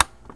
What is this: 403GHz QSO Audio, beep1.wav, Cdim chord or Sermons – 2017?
beep1.wav